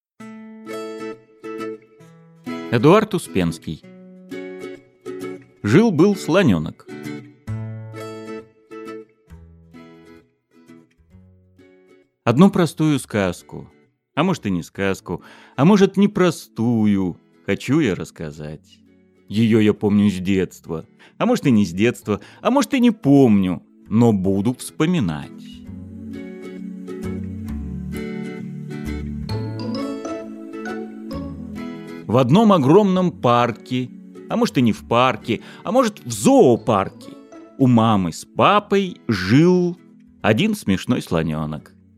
Аудиокнига Жил-был слонёнок | Библиотека аудиокниг